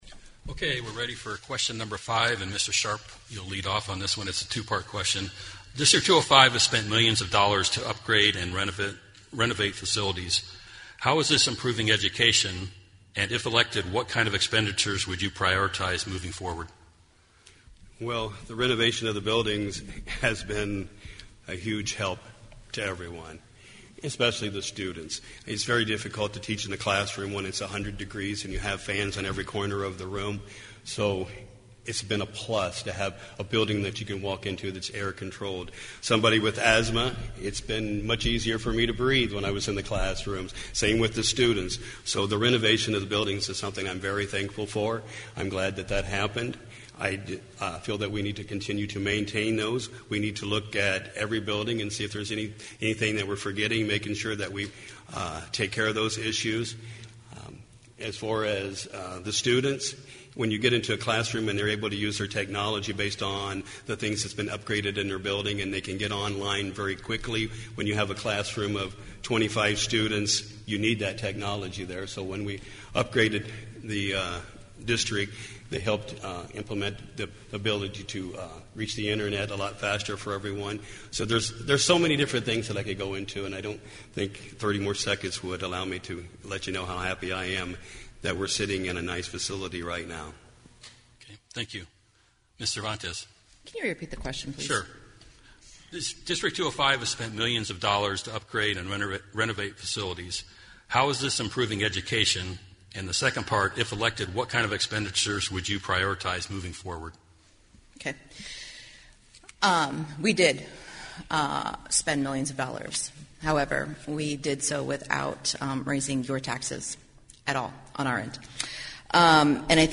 Candidates for Galesburg City Council and District 205 School Board participated in a Galesburg Candidate Forum on March 14 in Hegg Performing Arts Center at Galesburg High School.